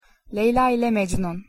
pronunciation_tr_leyla_ile_mecnun.mp3